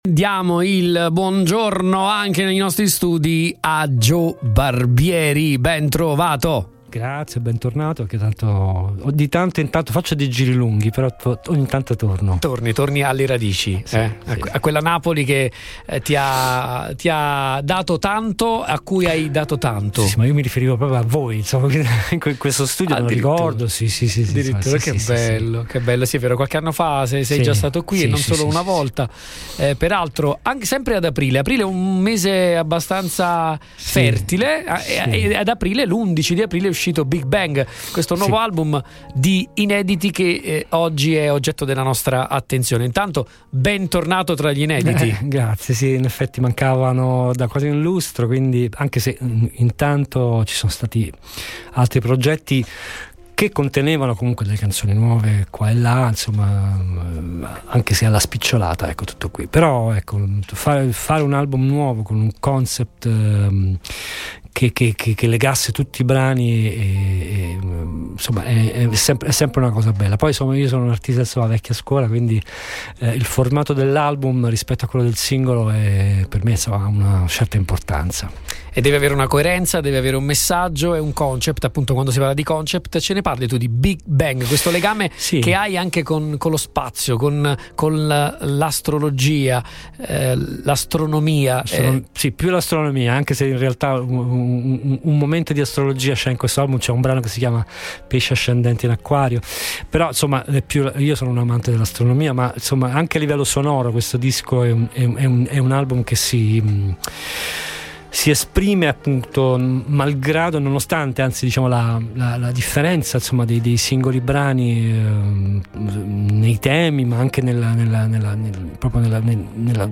“Bentornato a casa, Joe.” Con queste parole calorose si apre la chiacchierata negli studi di Radio Punto Nuovo, un ritorno per Joe Barbieri, artista napoletano dal cuore nomade ma con un legame indissolubile con la sua terra. E proprio in questo fertile mese di aprile, l’11 per la precisione, è esploso “Big Bang”, il suo atteso album di inediti, un’occasione per ritrovarsi “tra le proprie creature” dopo quasi un lustro.